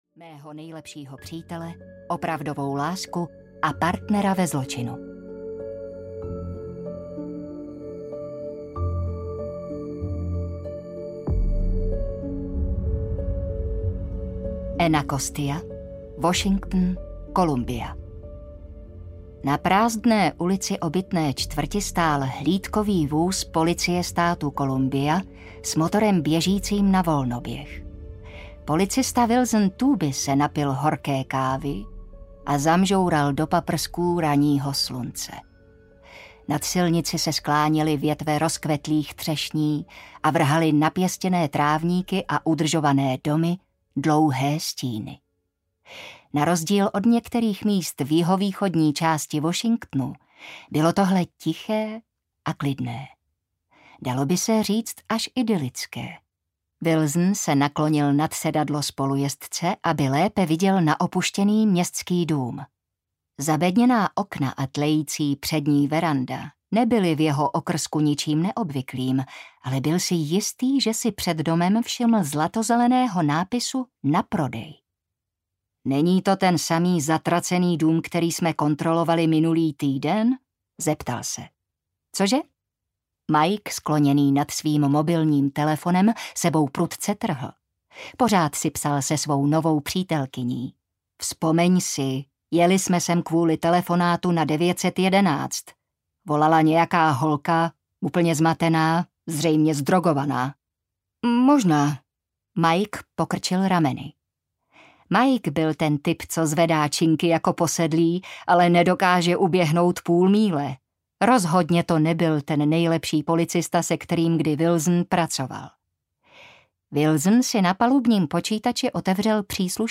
V kleci audiokniha
Ukázka z knihy